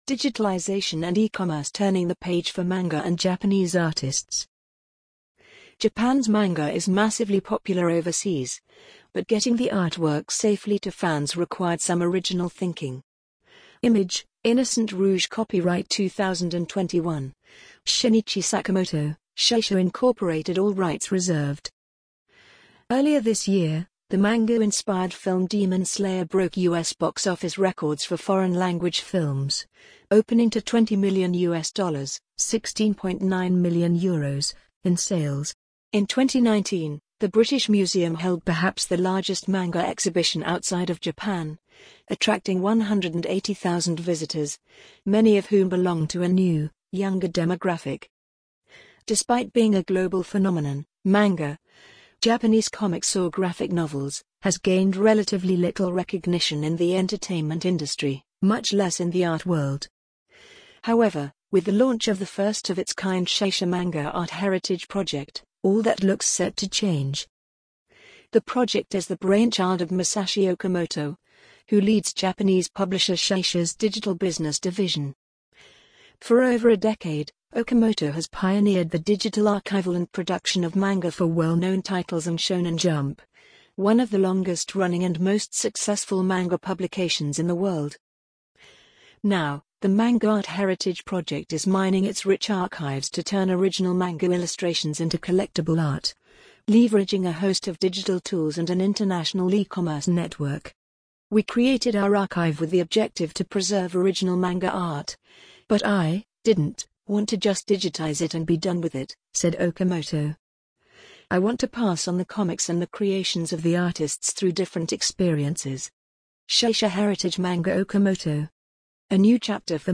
amazon_polly_25950.mp3